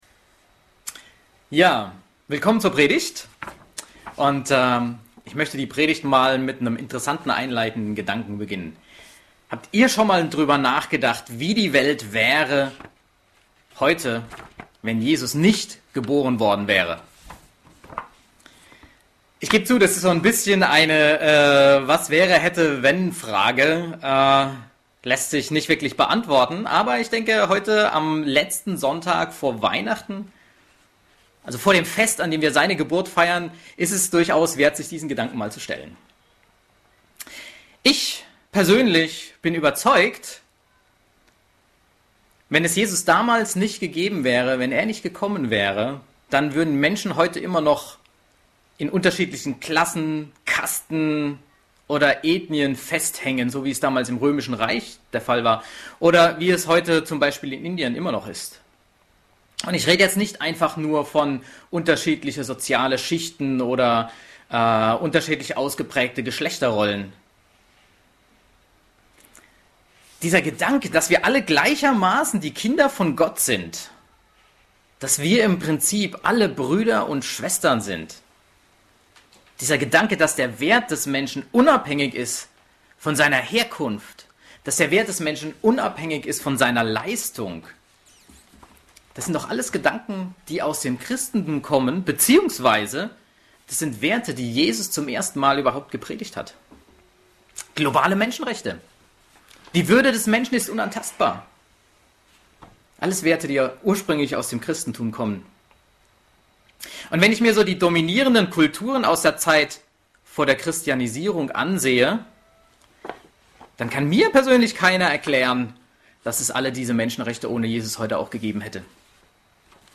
Predigten - Berliner Gemeinde Christi